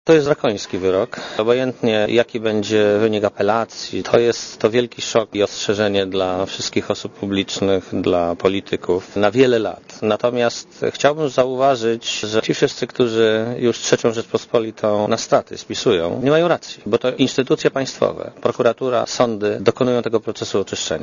Premier Belka oceniając decyzję sądu powiedział – „Obojętnie, jaki będzie wynik apelacji, to jest wielki szok i ostrzeżenie dla wszystkich osób publicznych i polityków na wiele lat”.
Komentarz audio